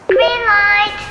Download Shrimp Game Green Light sound effect for free.